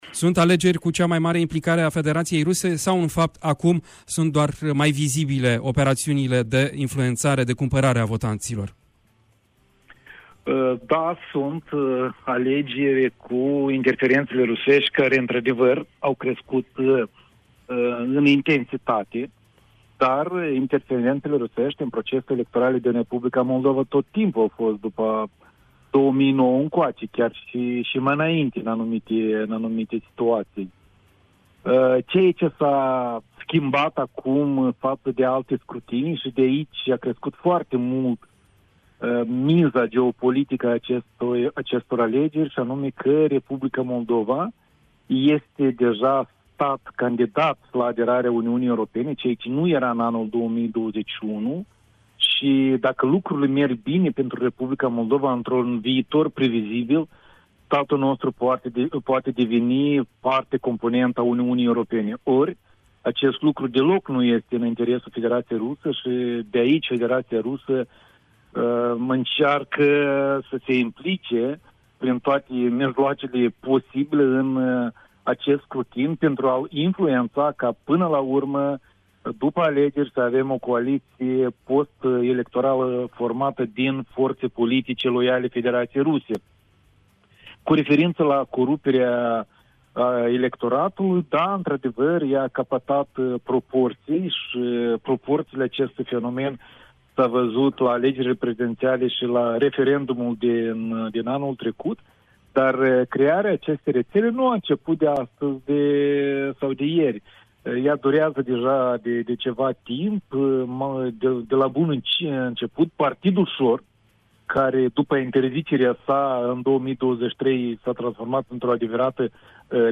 Varianta audio a interviului: Share pe Facebook Share pe Whatsapp Share pe X Etichete: